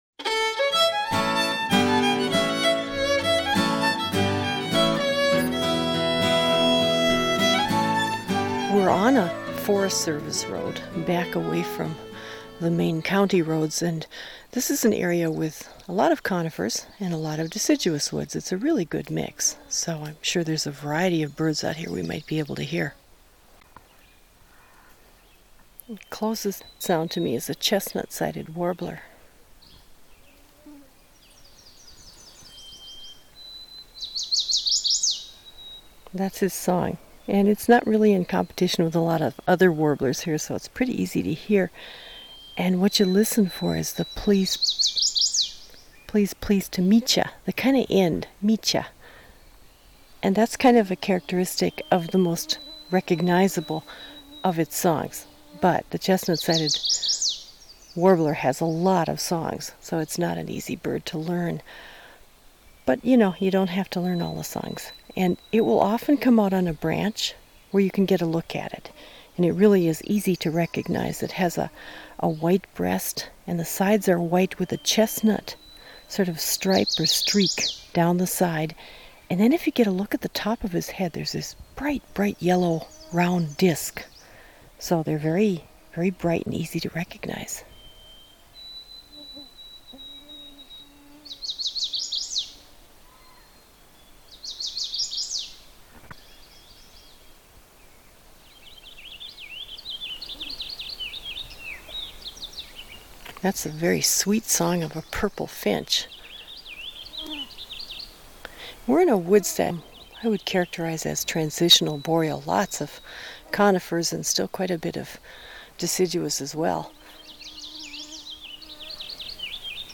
Field Notes: Chestnut-sided warbler, purple finch and red-eyed vireo